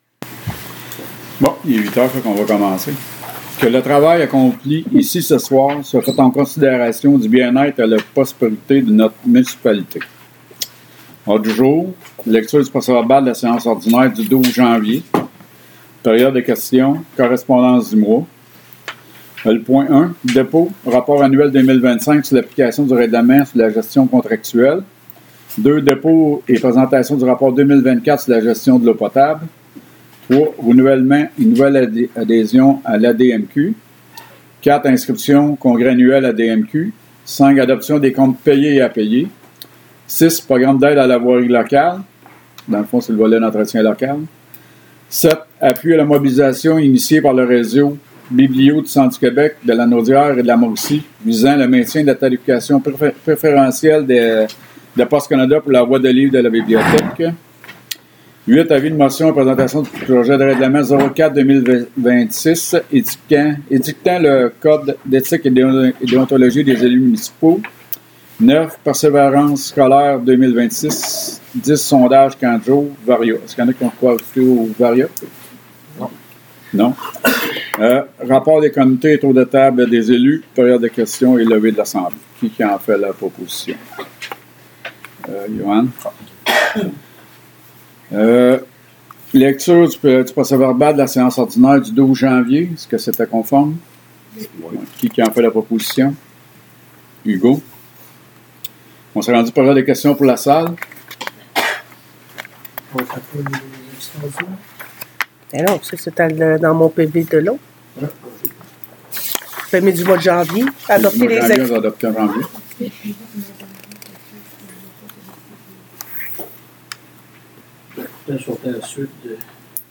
Séances du conseil - Municipalité de Saint-Zéphirin-de-Courval